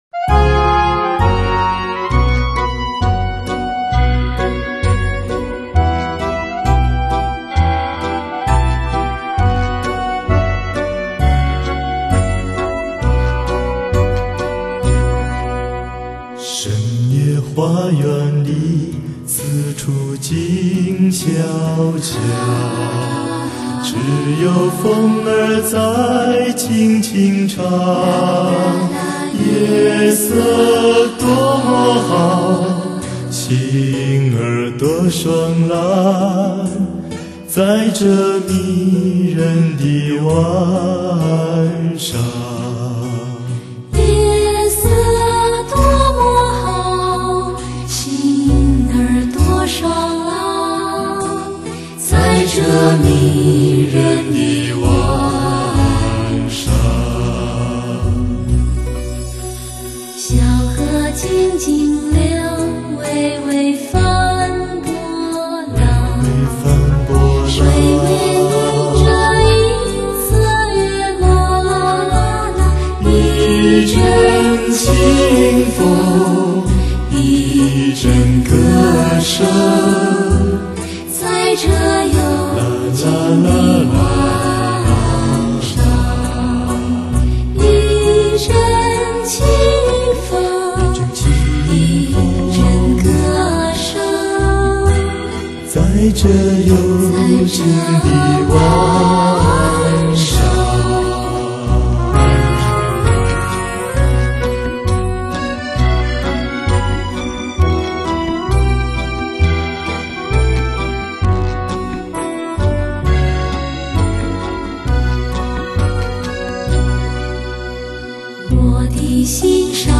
MIC:U-87 非常廣泛使用的一種電容麥尅風
低品質試聽麯目